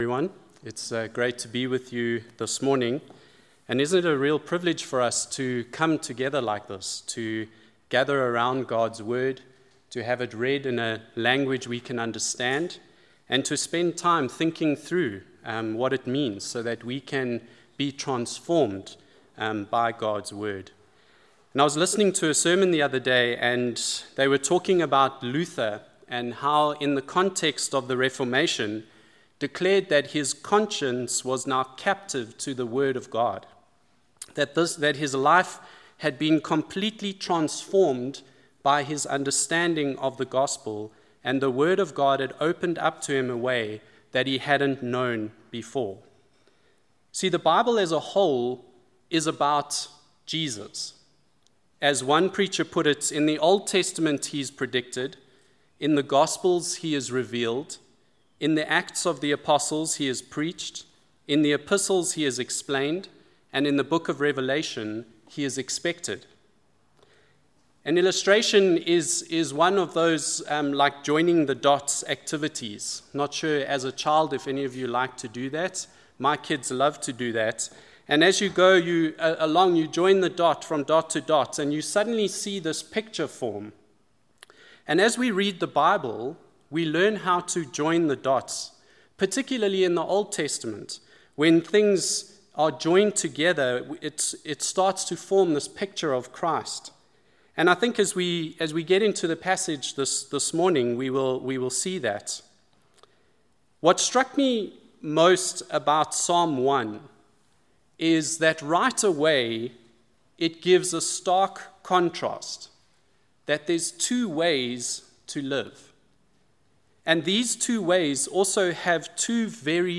Psalm 1 Service Type: Sunday Morning Following God and being obedient to Him.